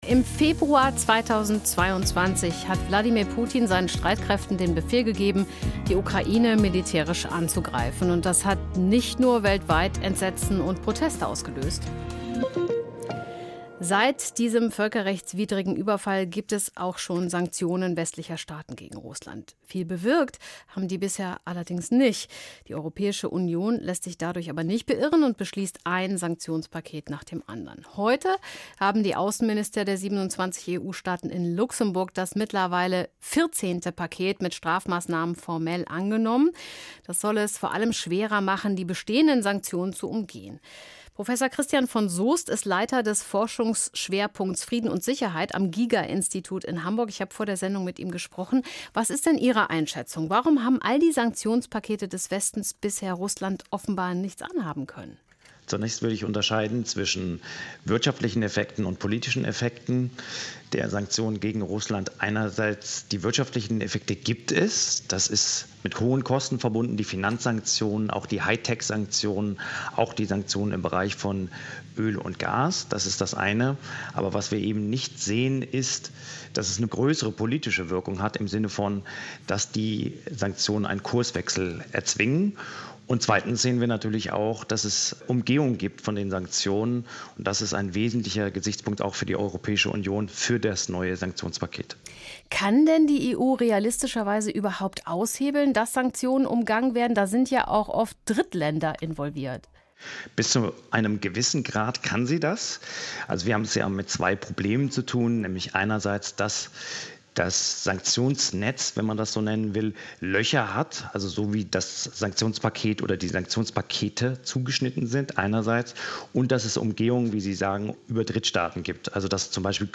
hr-iNFO | Interview | 24.06.2024